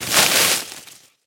vines.ogg